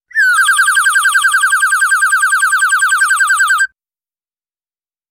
На этой странице собраны натуральные звуки чириканья птиц в высоком качестве.
Комичное чириканье птичьих голосов